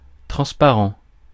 Ääntäminen
IPA : /trænsˈpærənt/